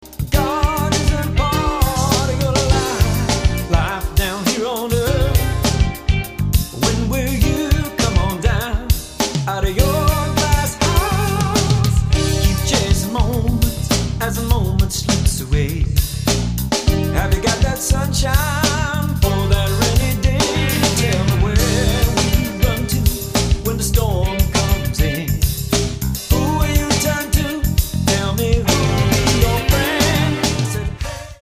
• Genre: Christian music with a dollop of rock.
The songs in pop, rock, blues and jazz mode